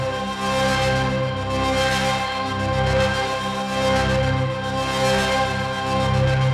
Index of /musicradar/dystopian-drone-samples/Tempo Loops/110bpm
DD_TempoDroneB_110-C.wav